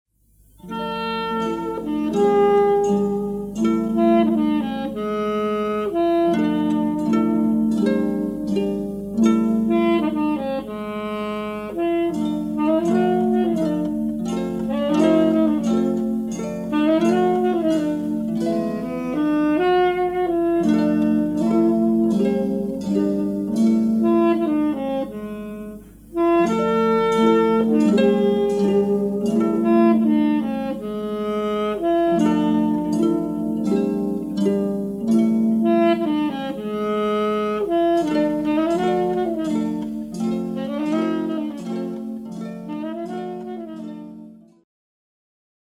magnificent and epic score